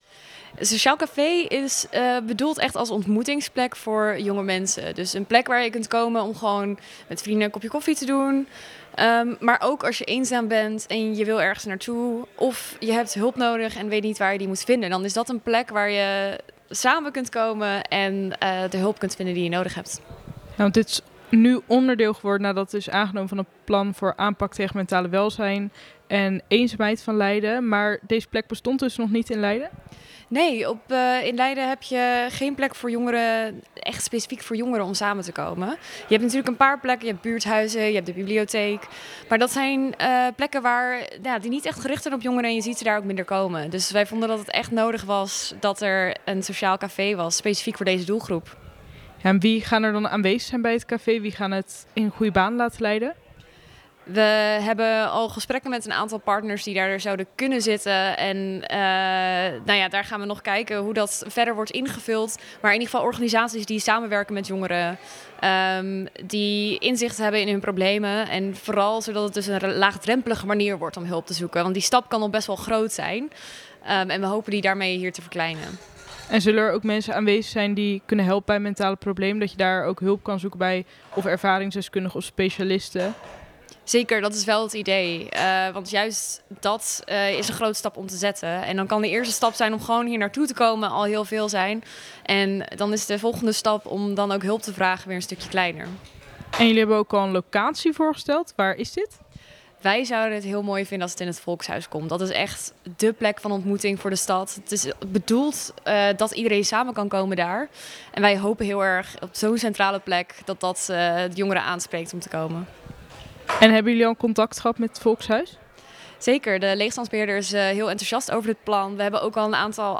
gaat in gesprek met fractievoorzitter Elianne Wijnands over het Sociaal Café